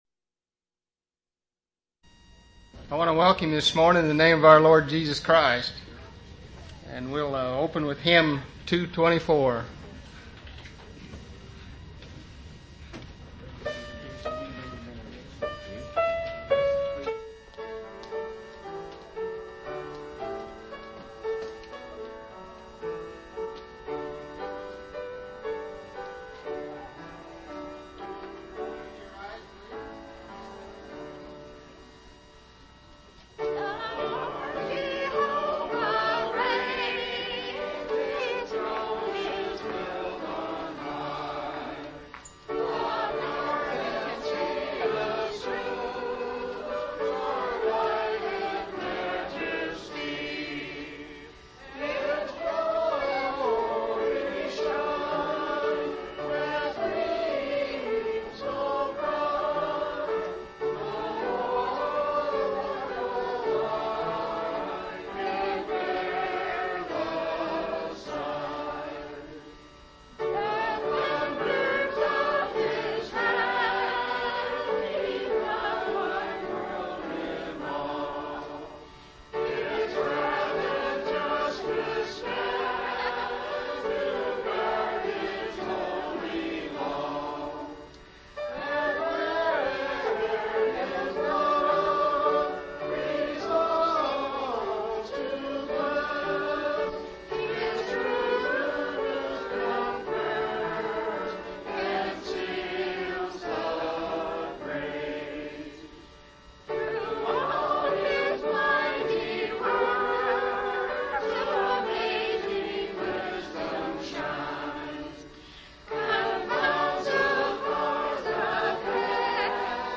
Missouri Reunion Event: Missouri Reunion